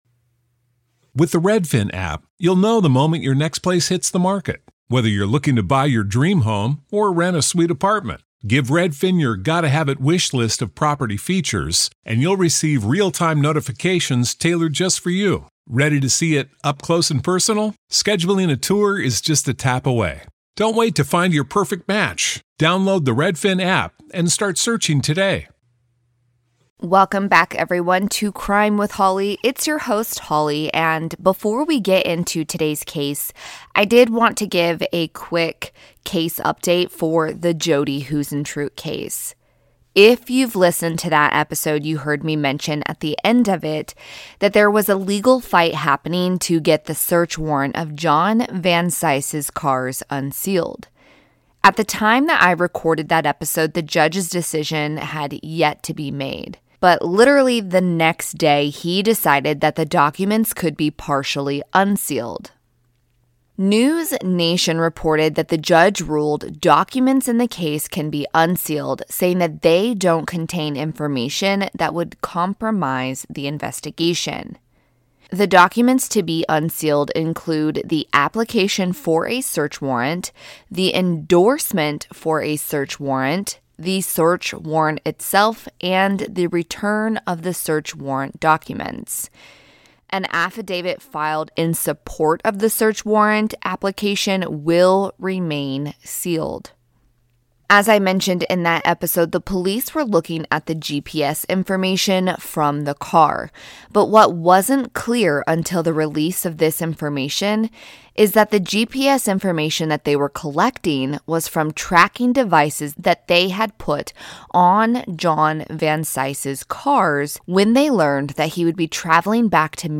New introduction and ending have since been recorded and added, audio levels may reflect the different recordings.